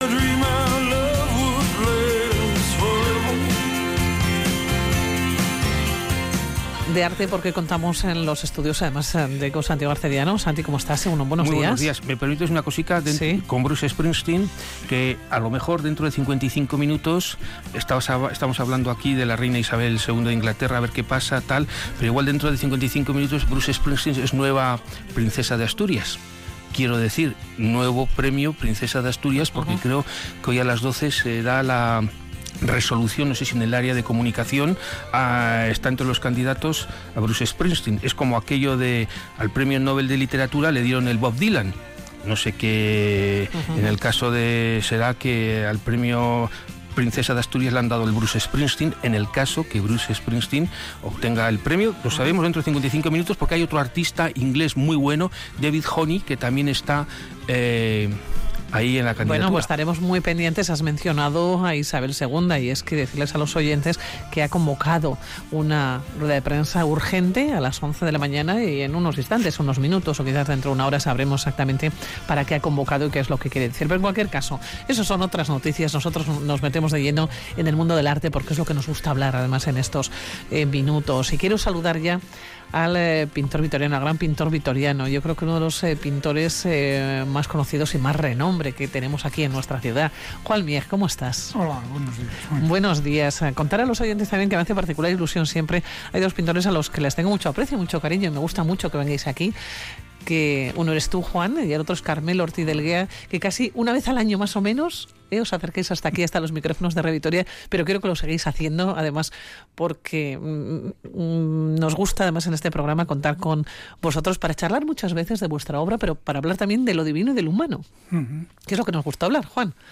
Hoy en espacio dedicado al arte entrevistamos este pintor vitoriano con una trayectoria profesional de 50 años a su espalda Whatsapp Whatsapp twitt telegram Enviar Copiar enlace nahieran